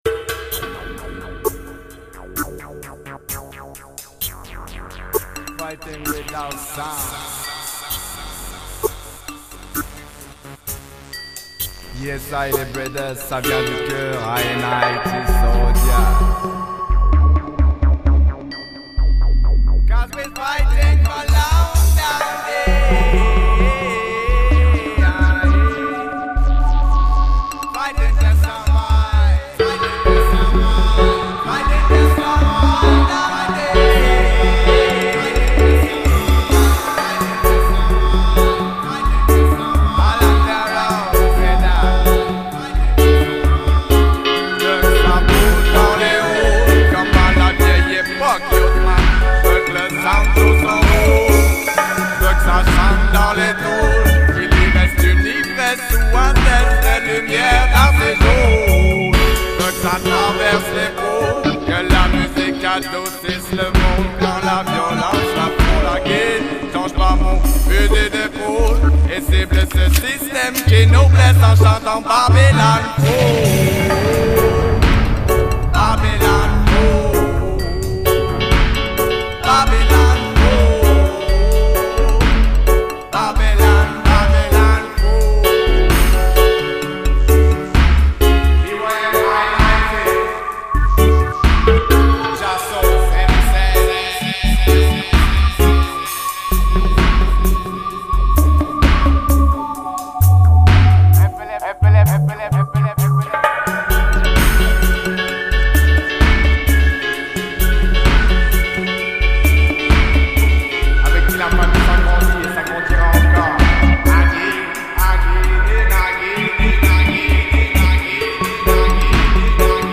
inna warrior style